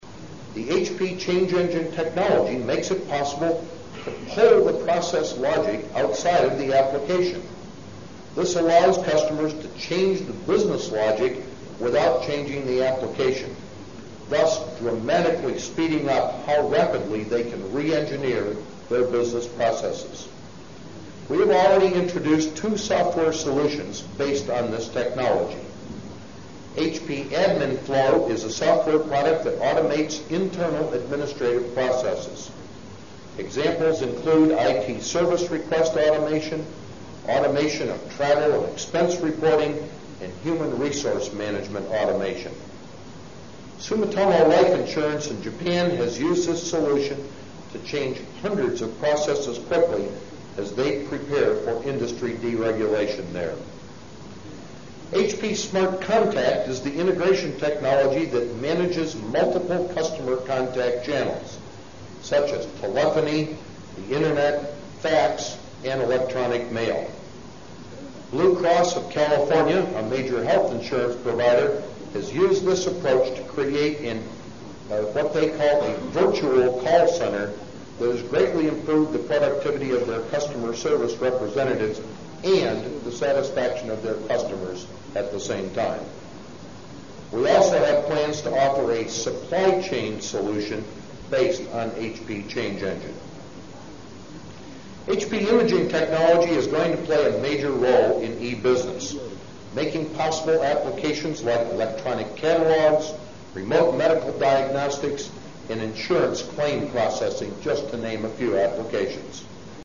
财富精英励志演讲 第163期:携手打造电子化世界(15) 听力文件下载—在线英语听力室
这些财富精英大多是世界著名公司的CEO，在经济领域成就斐然。在演讲中他们或讲述其奋斗历程，分享其成功的经验，教人执着于梦想和追求；或阐释他们对于公司及行业前景的独到见解，给人以启迪和思考。